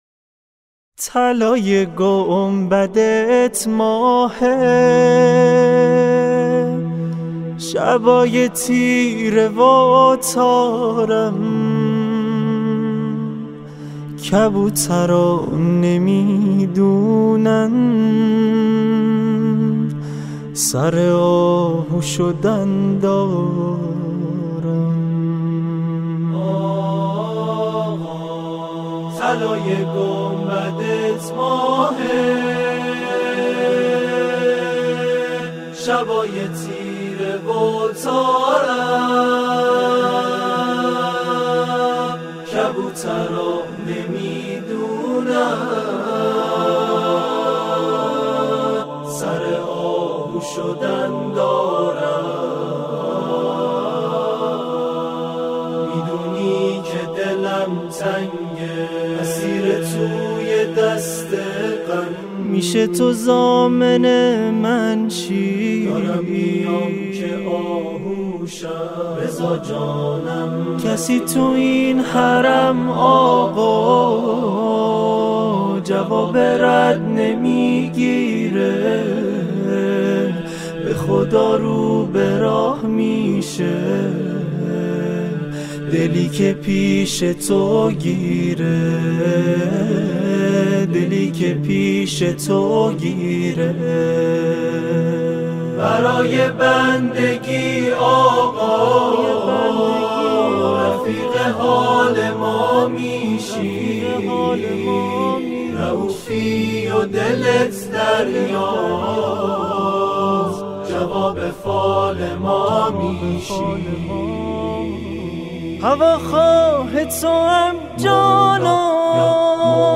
سرودهای امام رضا علیه السلام